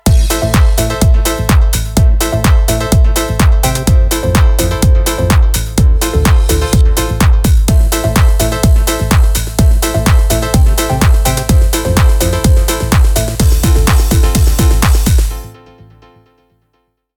Iconic 909 grooves in Clean, Chromed Tape, Dirty Tape & Xtra modes.
These sounds are directly sourced from the original machines, ensuring every beat carries their unmistakable character and warmth.
909_beatpack_demo.mp3